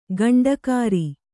♪ gaṇḍakāri